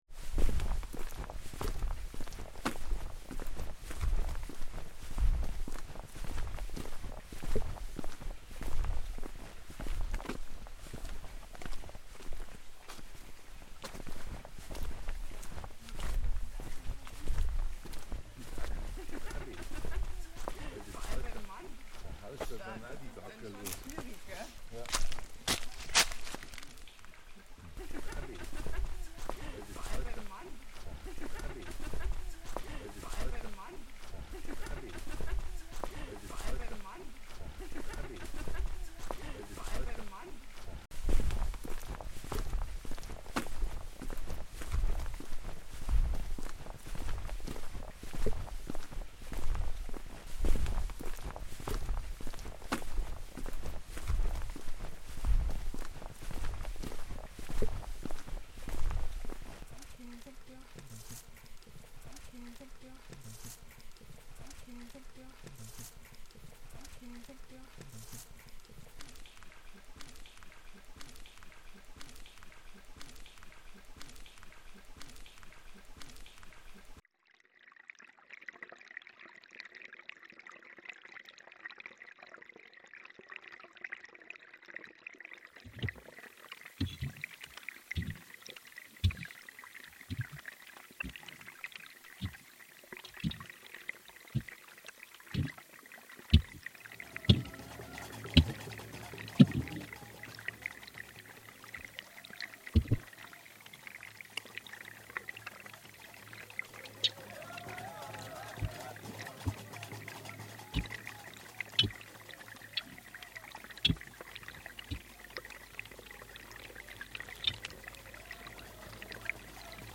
Already rhythmic in the sound of the footsteps I looped parts of this, and brought it back in at the end. I really liked the image the recording brought up of walking the narrow Levada (waterways) and the muffled greetings and people saying 'sorry' and 'your welcome' as they squeeze past each other.